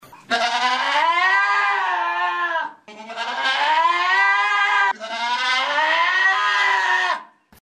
Здесь вы можете слушать и скачивать их естественные голоса: от блеяния молодых особей до предупредительных сигналов взрослых.
Звук крика козла серны